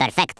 Worms speechbanks
perfect.wav